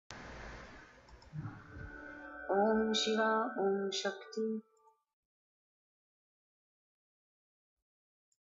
Chant: “Om Shiva, Om Shakti”